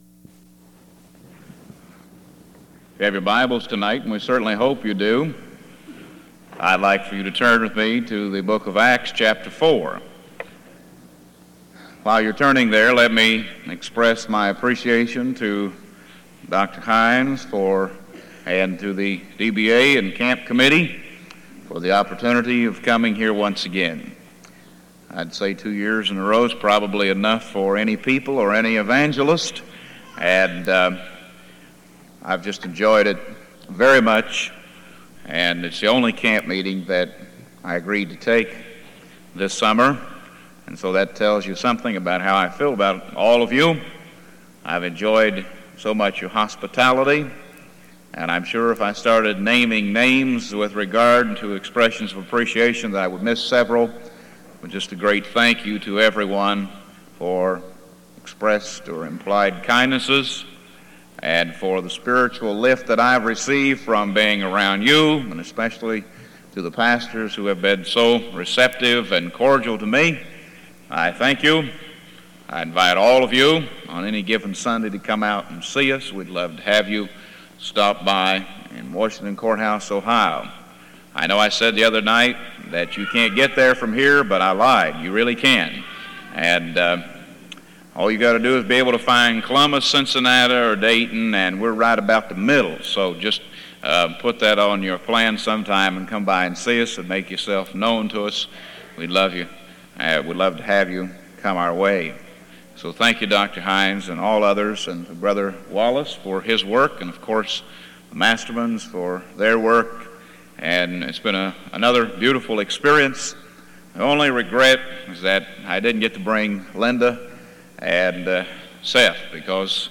Houghton Bible Conference 1982